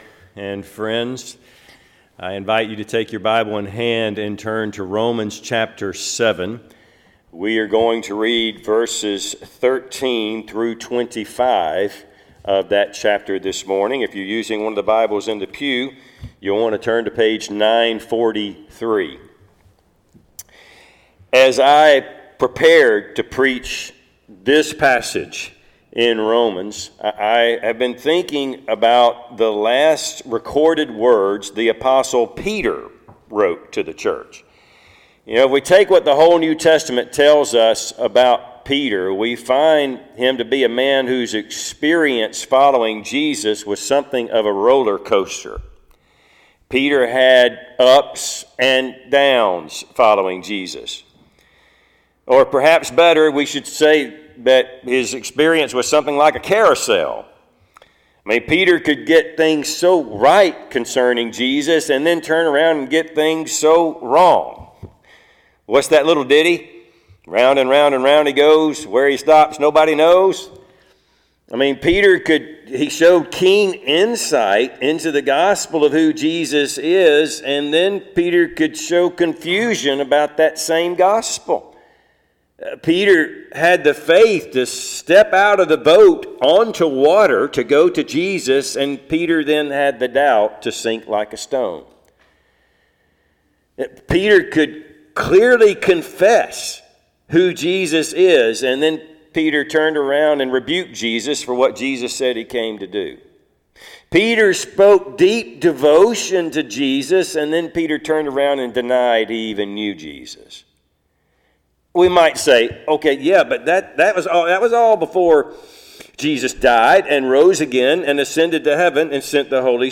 Passage: Romans 7:13-25 Service Type: Sunday AM